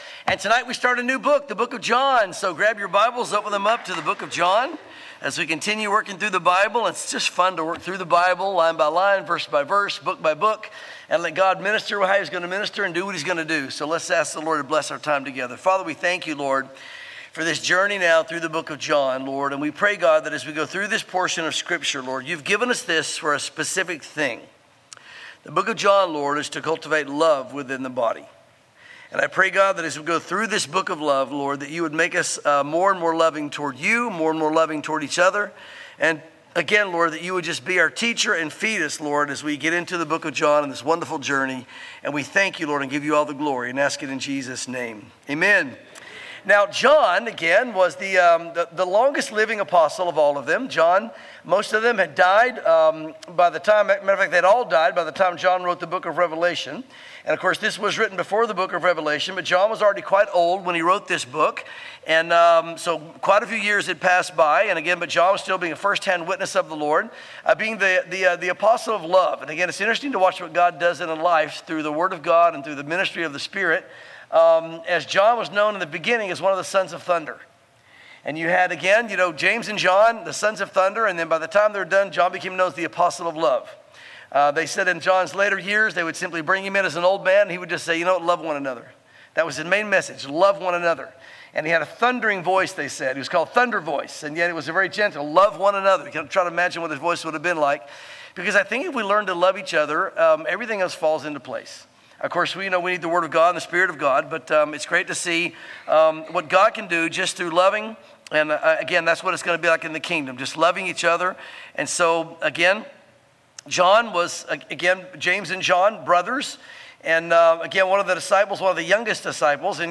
sermons John Chapters 1-3